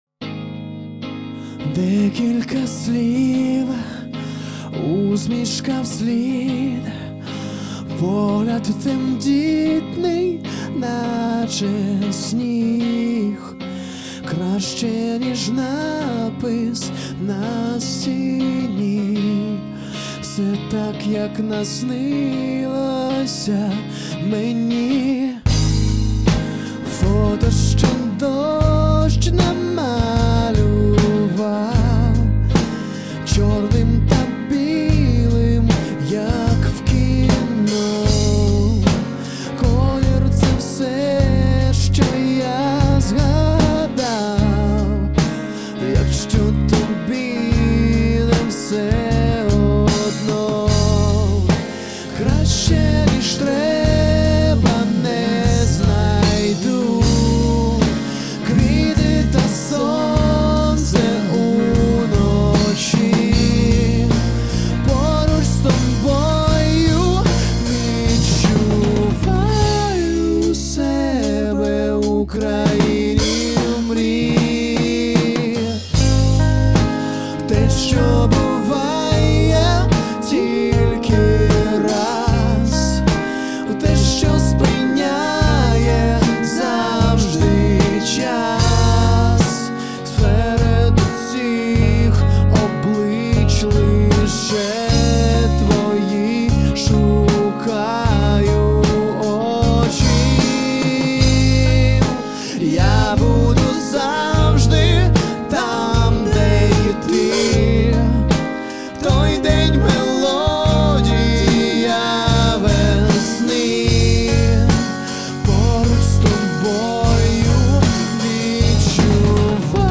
Коллектив существует с 2006 года, играет рок.
бас-гитара
ударные